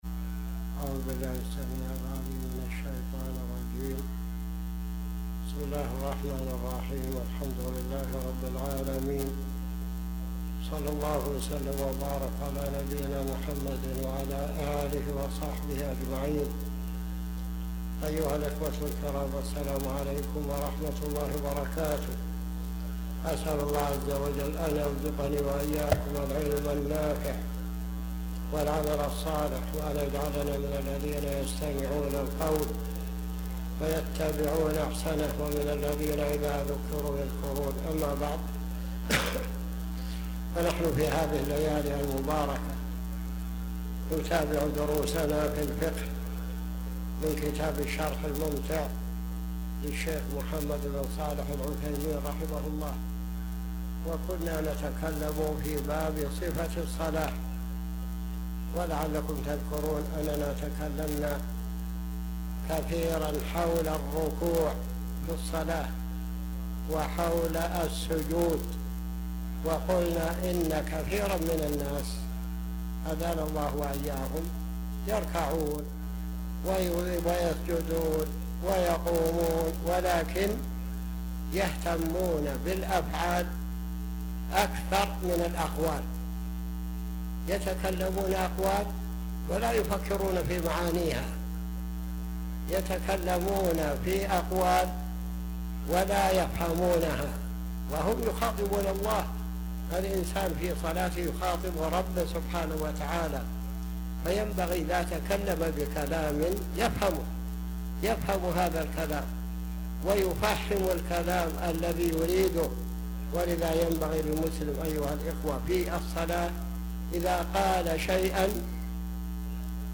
تاريخ النشر ١٠ ذو القعدة ١٤٤٠ هـ المكان: المسجد الحرام الشيخ